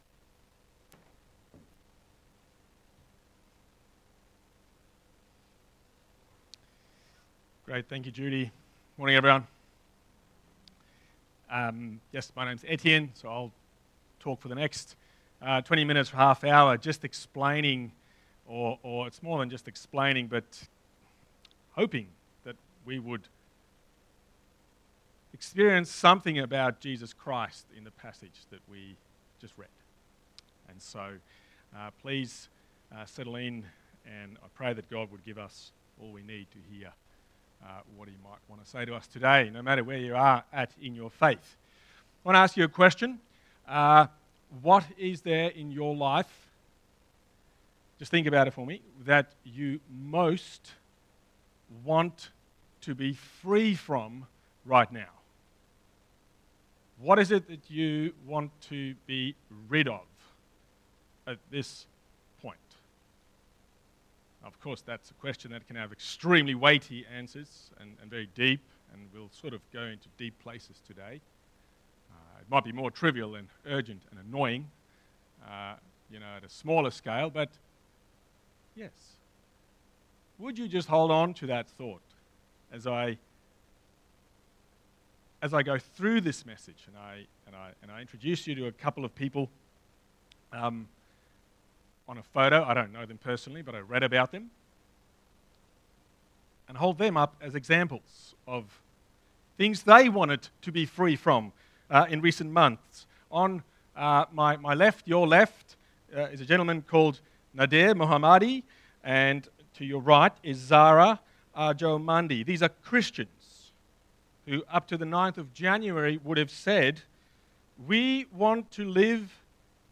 Text: Mark 11: 1-11 Sermon